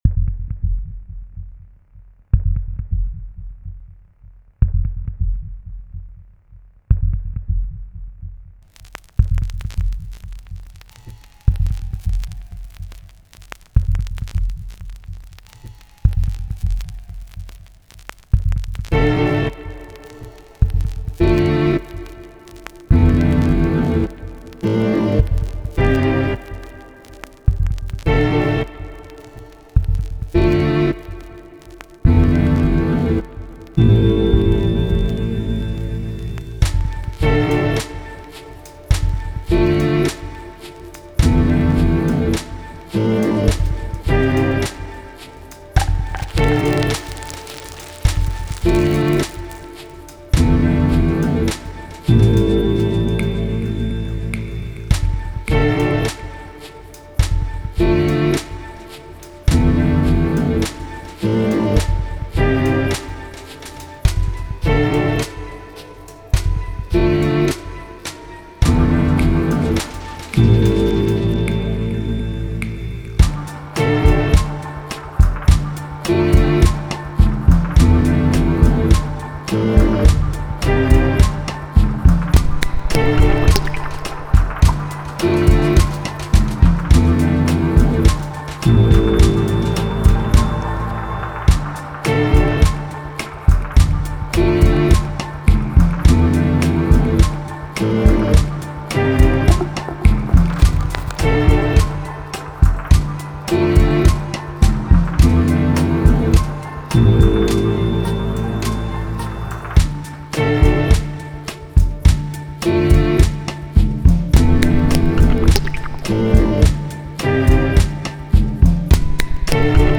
C’est la naissance du CIRQUE ELECTRO (électroacoustique et électronique)
Au plateau un duo qui se risque et se rattrape ensemble aux sons étranges de l’orgue de tiges filetées caressées par l’archet ou dans la résonnance pure de bastaings de bois malmenés. Ils tanguent ensemble sur les rythmiques d’une platine vinyle transformée en boite à rythme bancale.
Entre concert et performance plastique, une proposition où, si le silence est roi, le groove et le swing se nichent peut-être entre un boulon et une planche de bois.